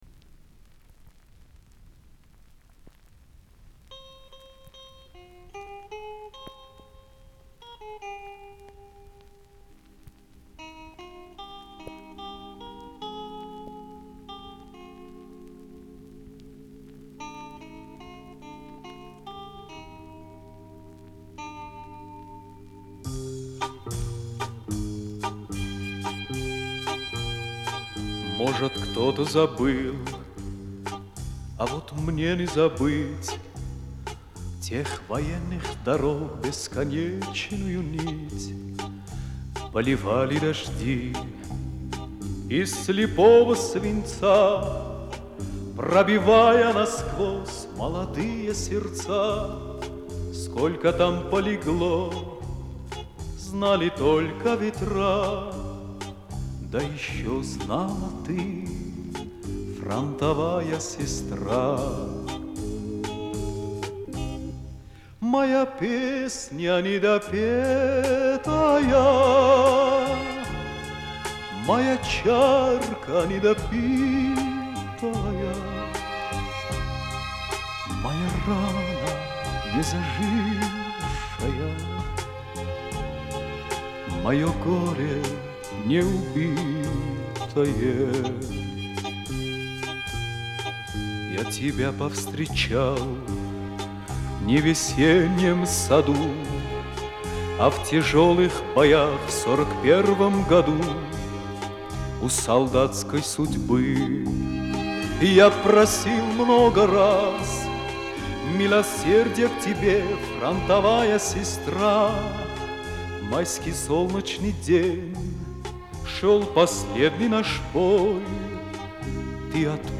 Оцифровка пластинки